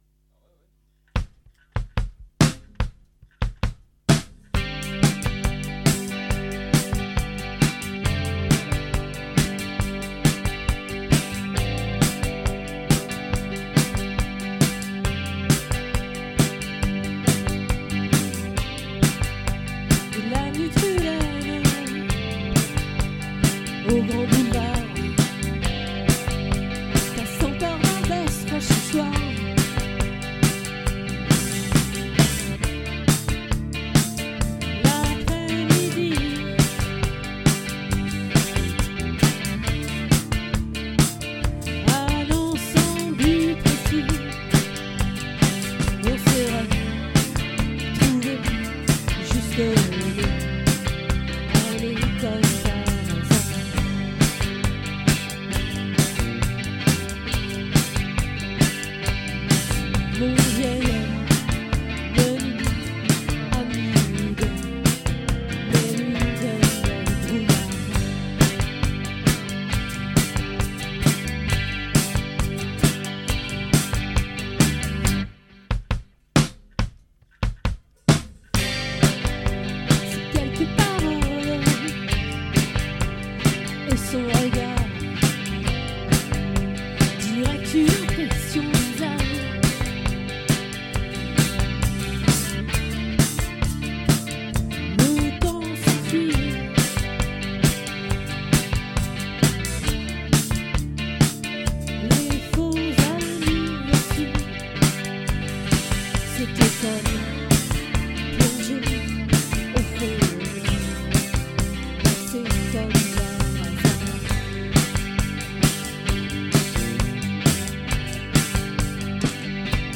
🏠 Accueil Repetitions Records_2023_06_14_OLVRE